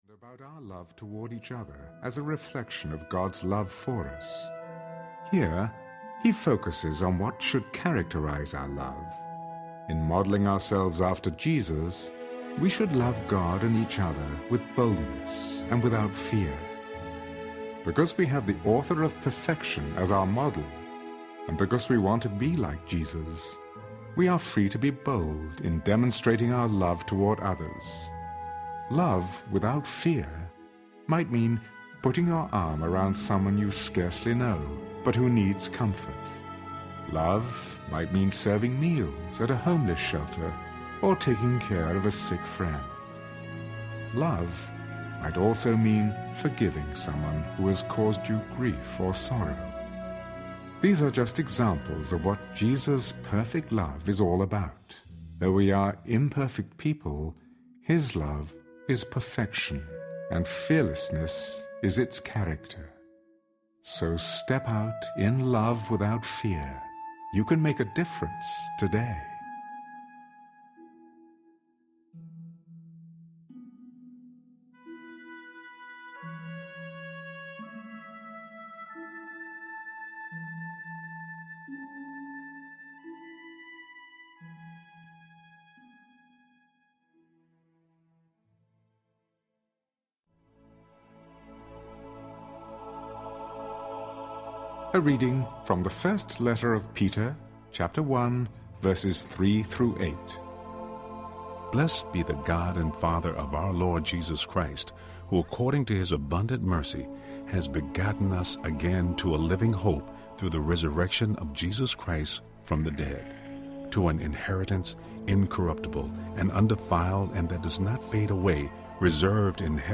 There are 40 three-minute devotions, equaling two hours of inspired listening time. Devotions include excerpts from The Word of Promise™ New Testament Audio Bible, an insightful reading by Michael York, and meditative music from composer Maestro Stefano Mainetti.